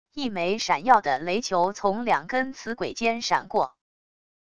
一枚闪耀的雷球从两根磁轨间闪过wav音频